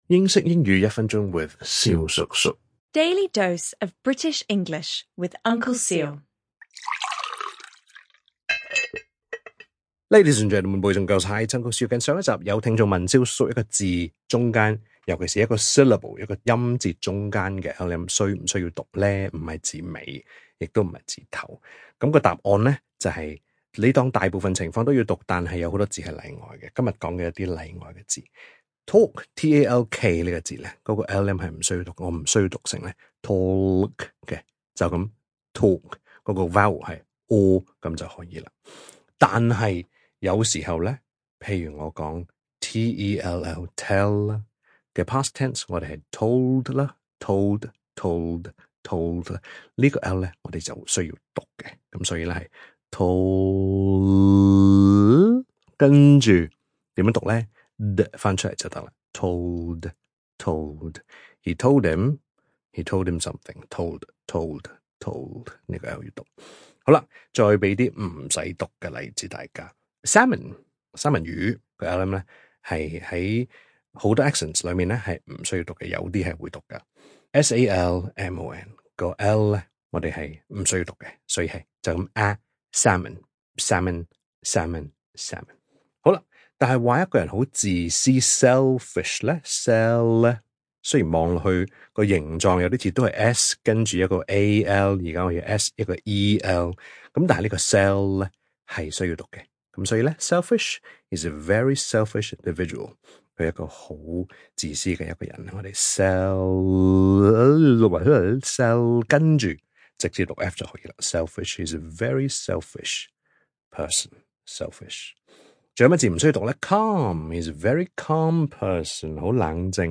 EP 1208 - The / ɔ: / sound vs The /ɒ/ sound (1)